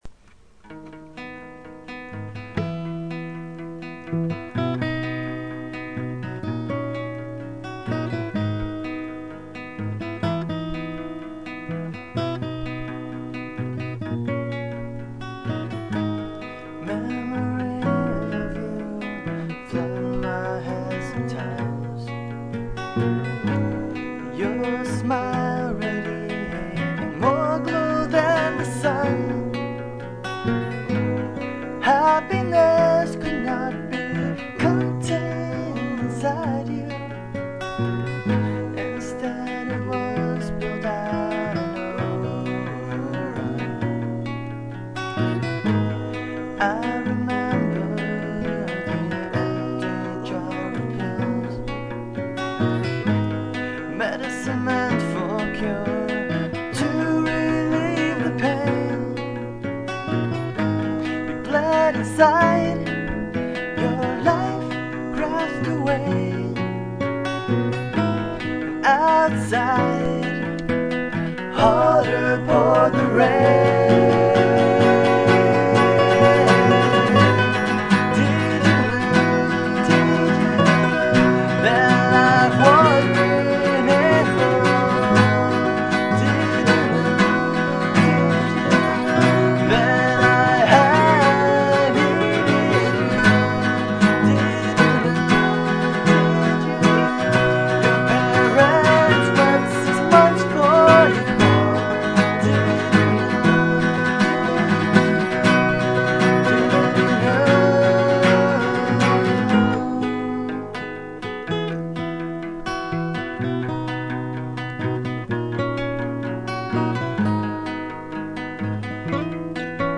Tearful Stolen Moments: a musical eulogy